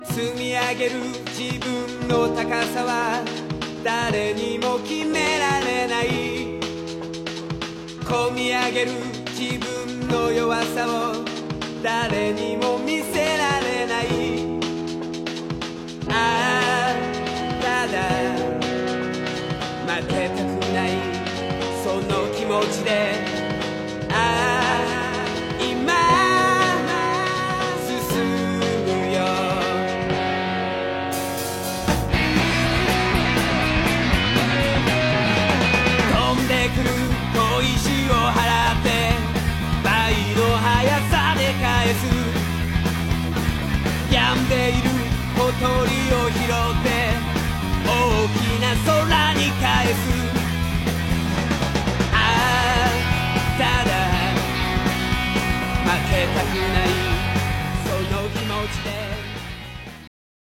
Música d'una sèrie d'anime japonesa